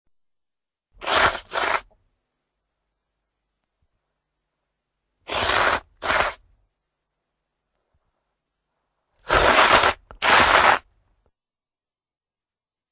SFX吃面速的一声吸面的声音音效下载
SFX音效